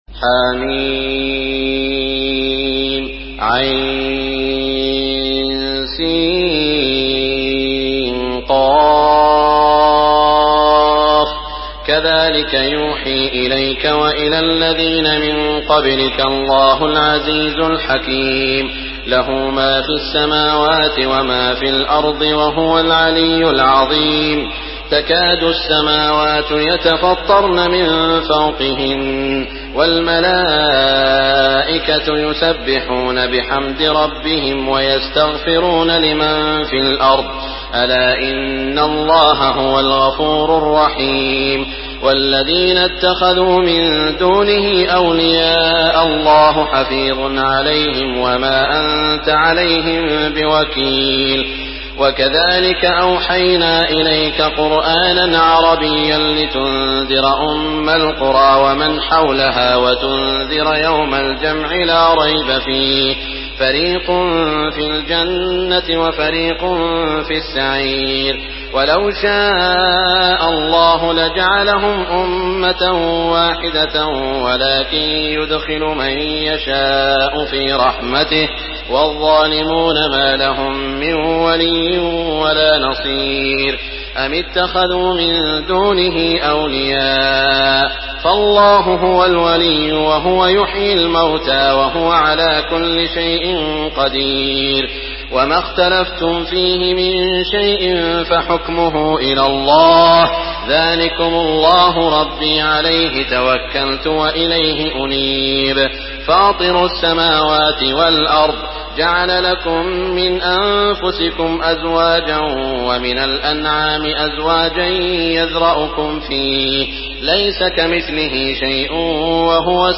Surah Ash-Shura MP3 by Makkah Taraweeh 1424 in Hafs An Asim narration.
Murattal